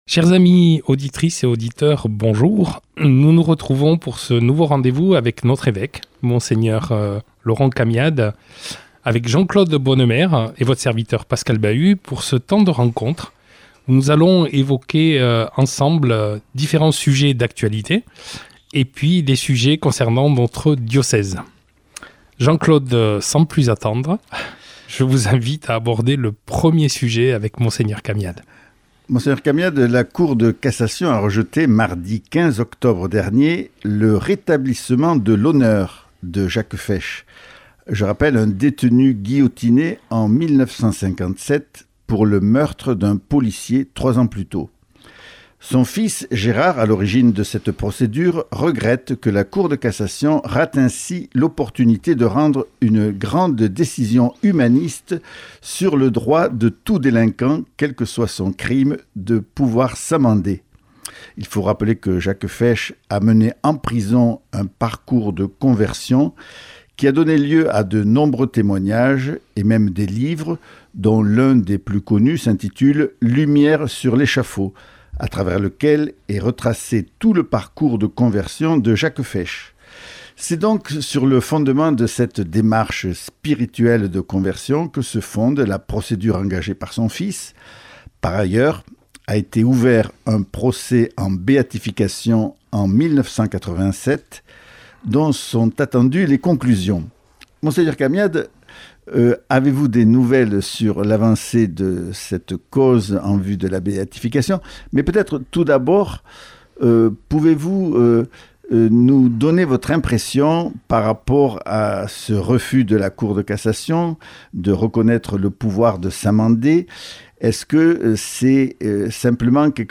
Entretien avec notre évêque